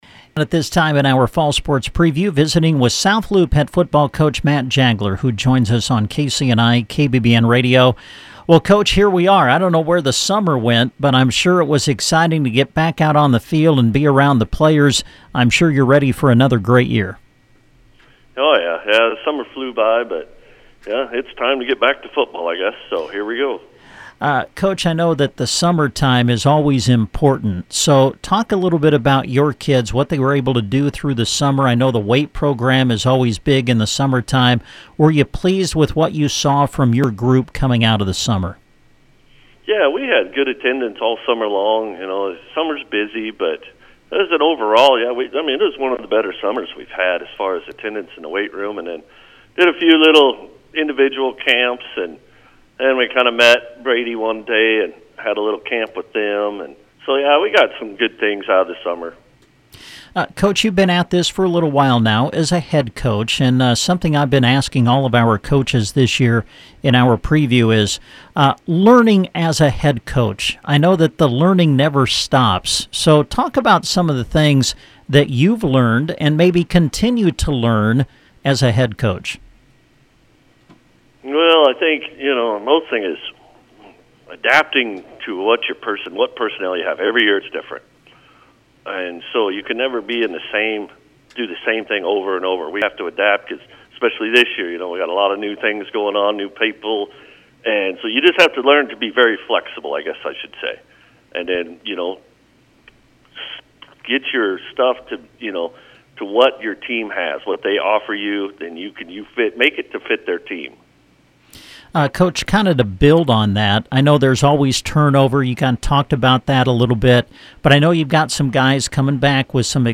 South Loup Football Preview – Interview